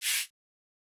Southside Vox (24).wav